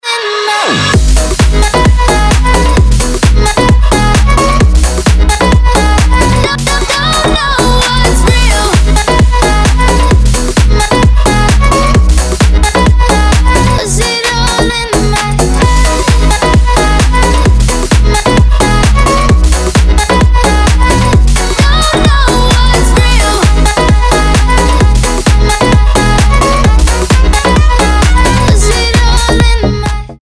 • Качество: 256, Stereo
dance
future house
club
vocal